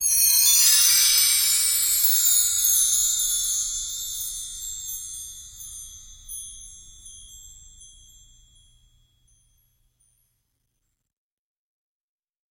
Окунитесь в мир необычных звуков: здесь собраны записи шорохов, оседания и движения пыли.
Звук космической пыли